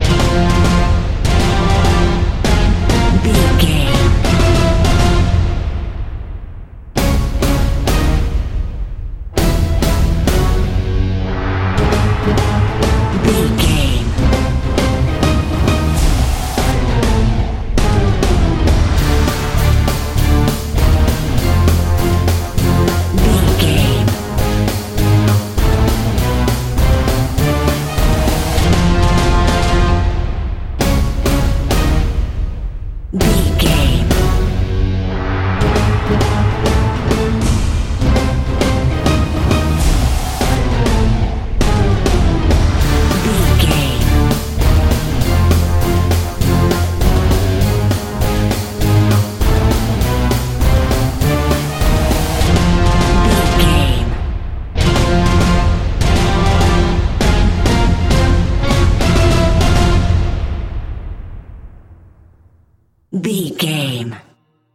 Epic / Action
Aeolian/Minor
brass
cello
percussion
synth effects
driving drum beat